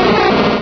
Cri de Pomdepik dans Pokémon Rubis et Saphir.